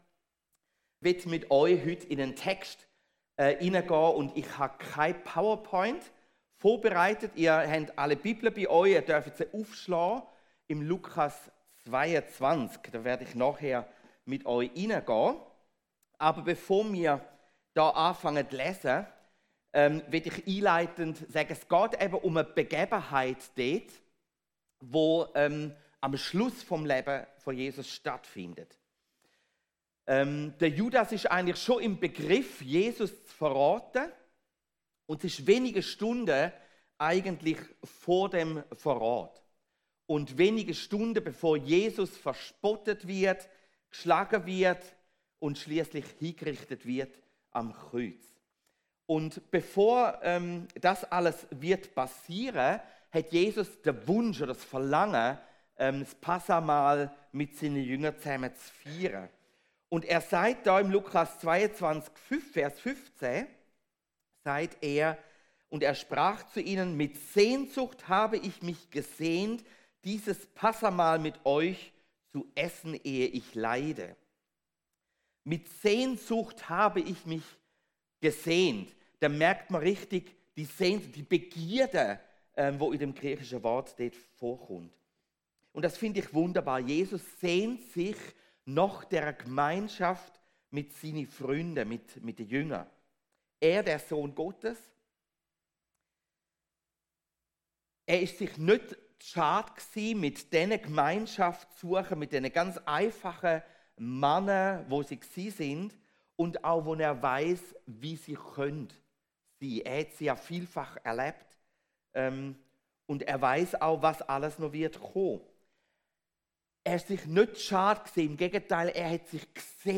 Hier hörst du die Predigten aus unserer Gemeinde.